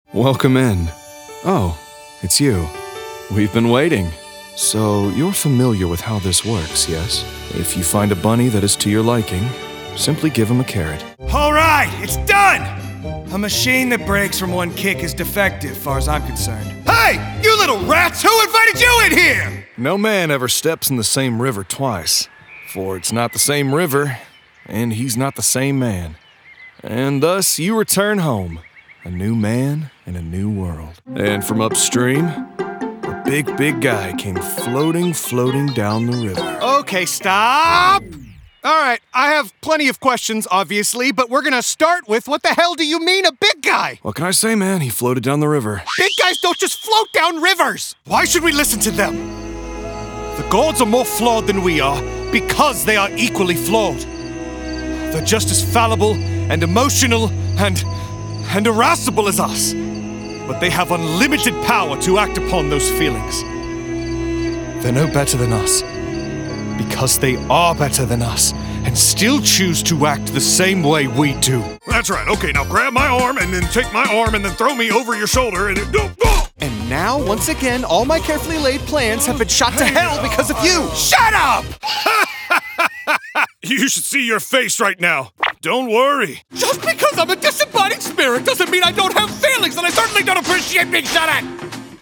Teenager, Young Adult, Adult, Mature Adult
Has Own Studio
ANIMATION 🎬